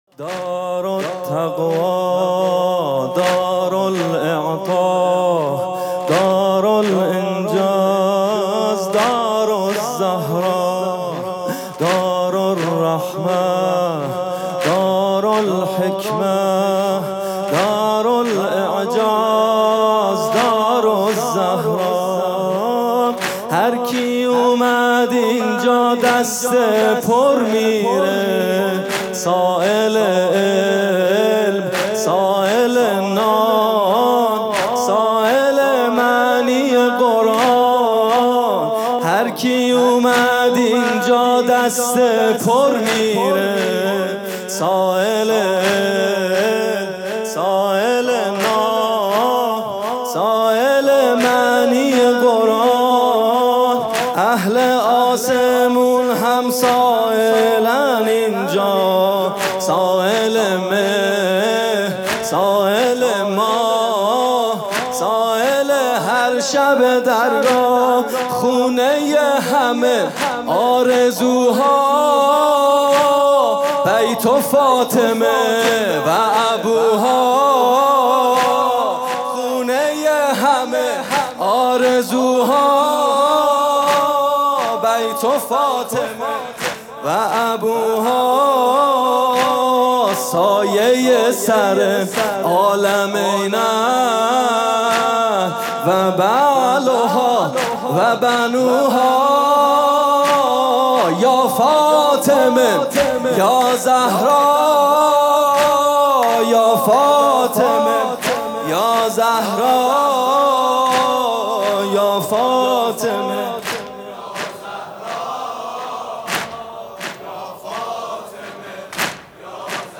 music-icon تک: در شهادتنامه مُهر از مِهر مادر داشتندباکری...